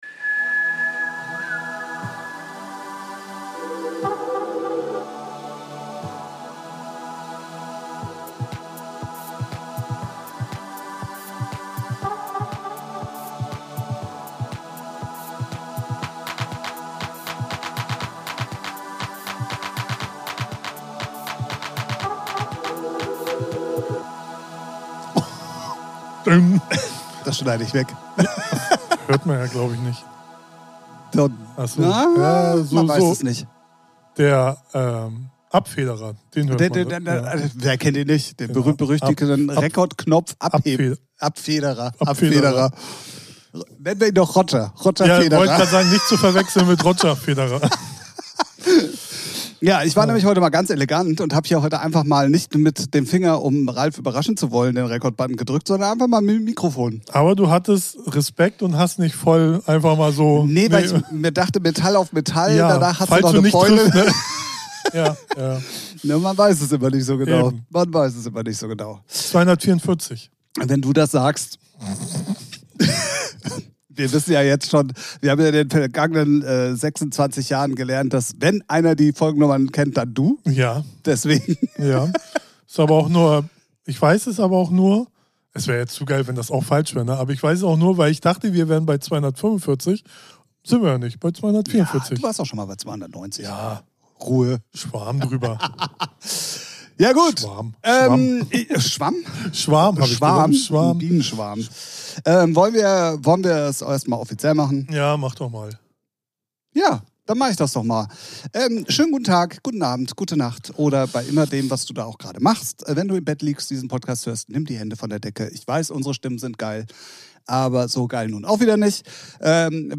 Diese Folge ist lauter als dein Nachbar mit der Bohrmaschine!